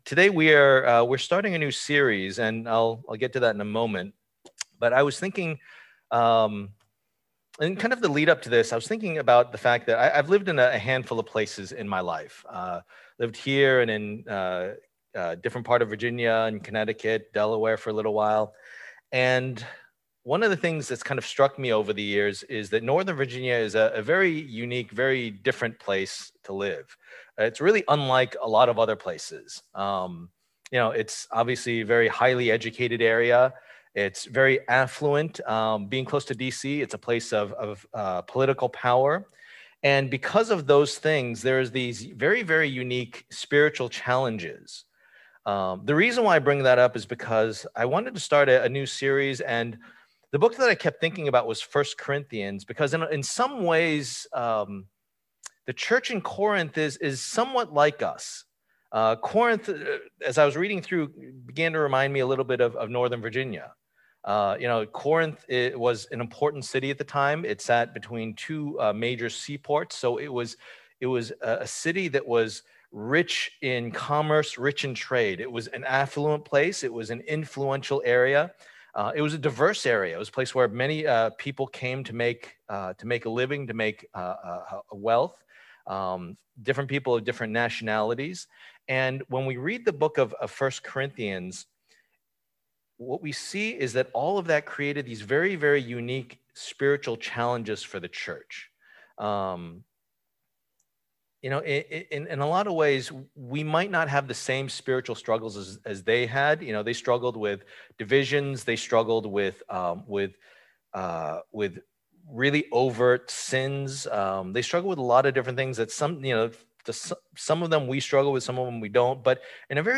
Service Type: Lord's Day